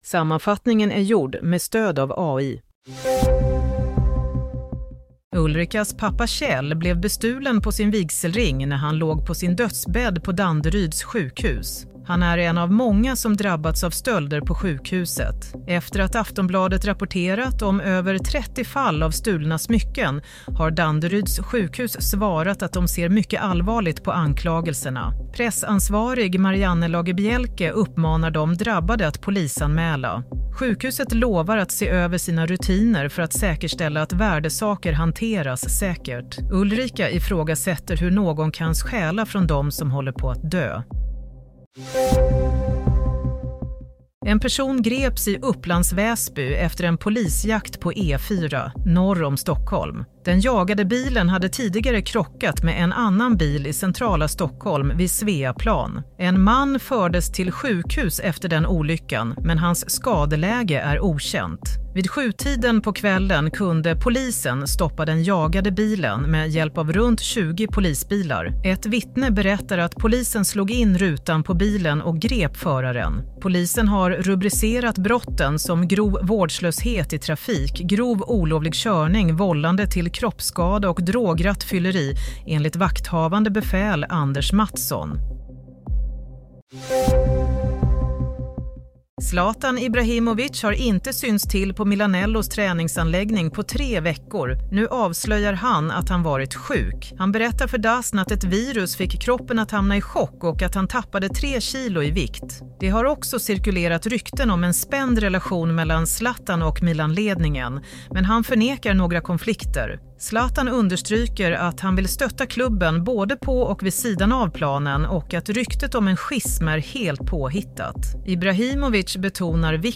Nyhetssammanfattning - 30 mars 23.00